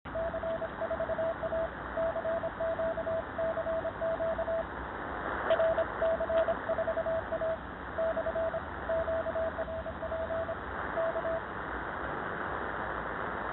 Антенна - провод вверх на ветку дерева.
Уровень приёма можно оценить по приложенной записи.